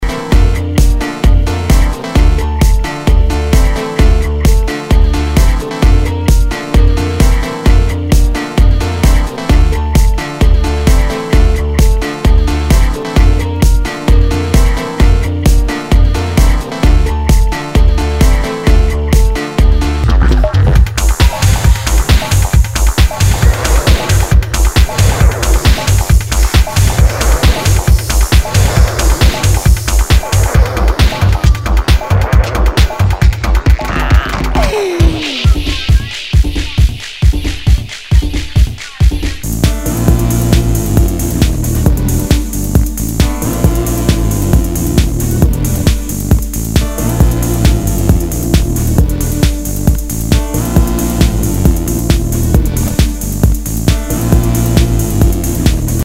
HOUSE/TECHNO/ELECTRO
ナイス！テック・ハウス！